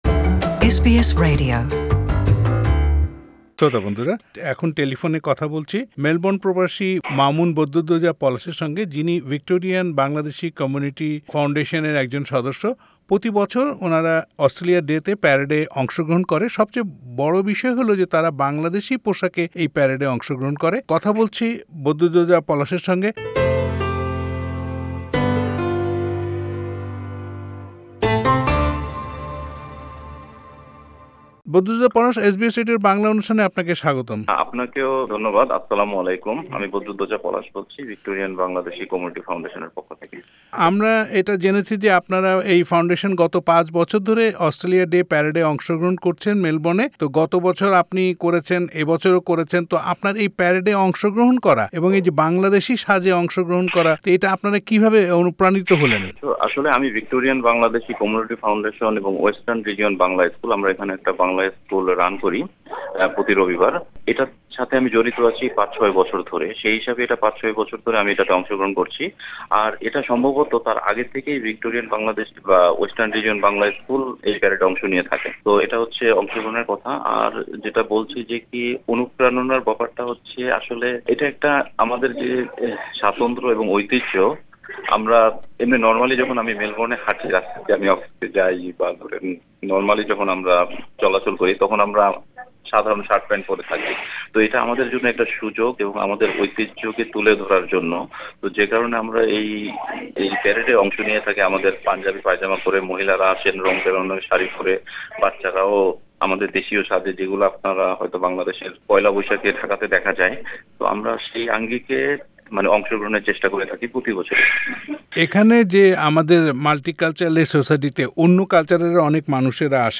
SBS Bangla